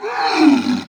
ZomBear Death.wav